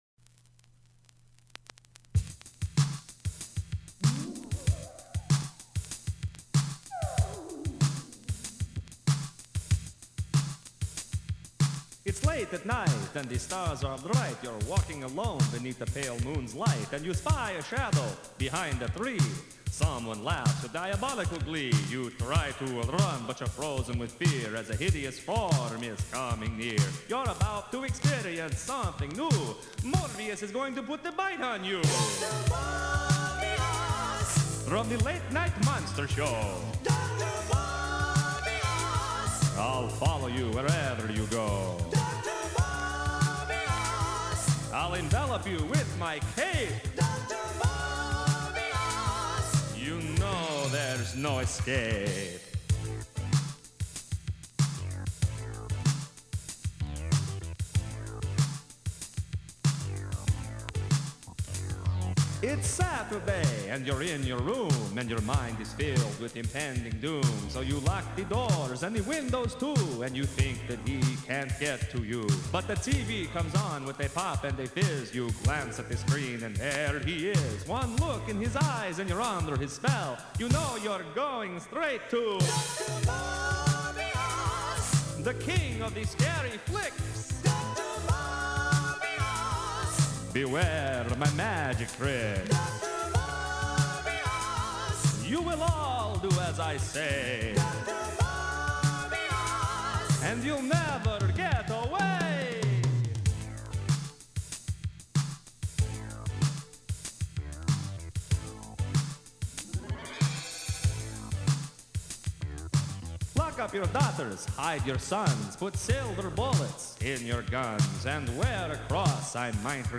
a novelty song